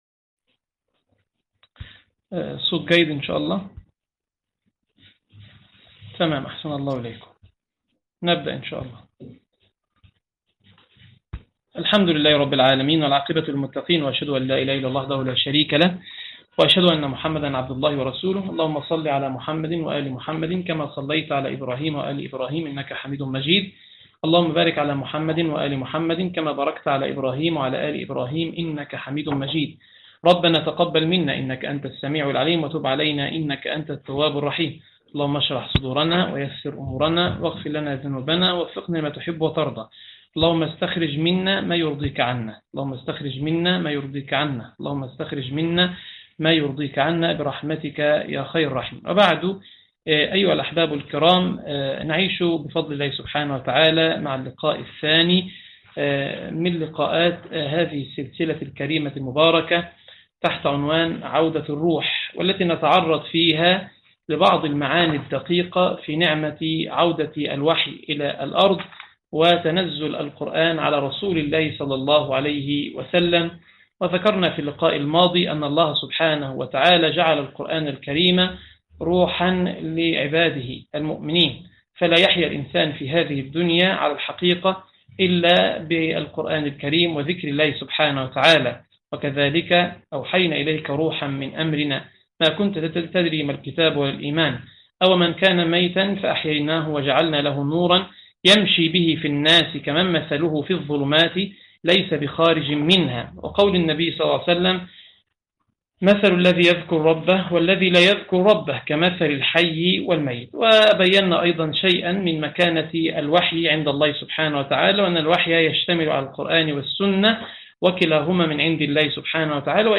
تفاصيل المادة عنوان المادة الدرس 2 | عودة الروح تاريخ التحميل الجمعة 15 اغسطس 2025 مـ حجم المادة 25.30 ميجا بايت عدد الزيارات 56 زيارة عدد مرات الحفظ 27 مرة إستماع المادة حفظ المادة اضف تعليقك أرسل لصديق